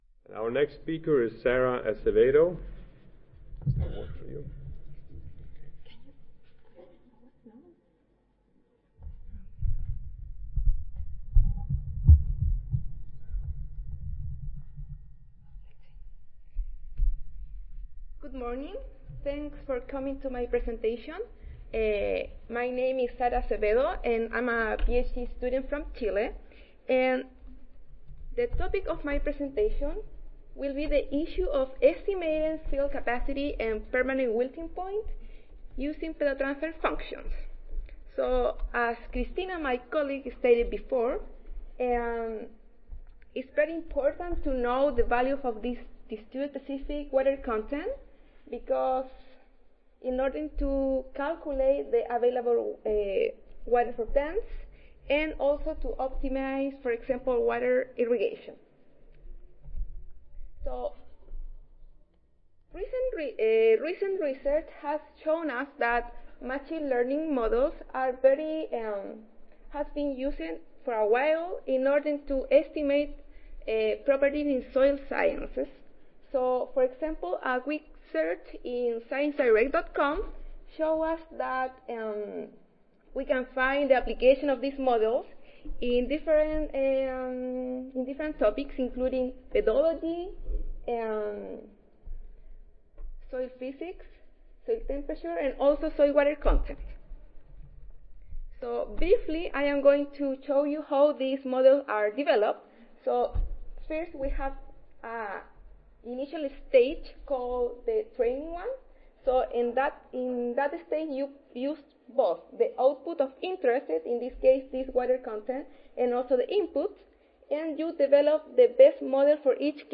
Pontificia Universidad Católica de Chile Audio File Recorded Presentation